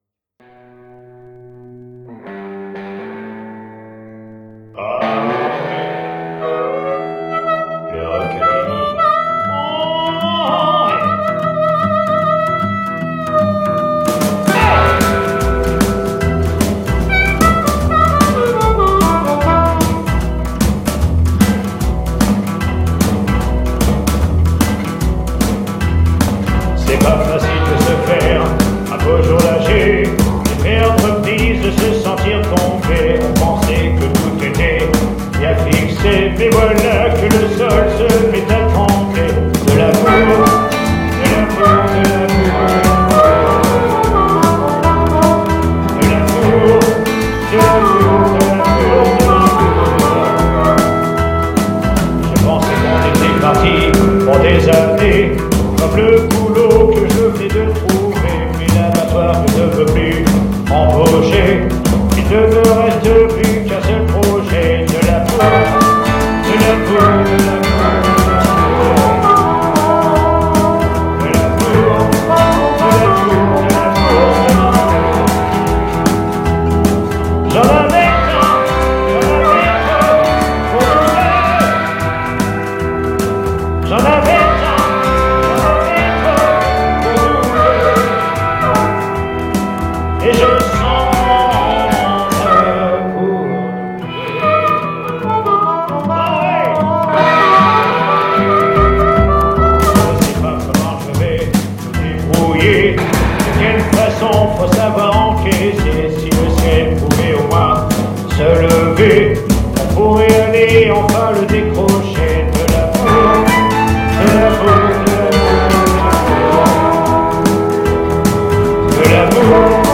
SOIREES BLUES-ROCK RETROSPECTIVE
DUO CHANT/HARMONICA
maquettes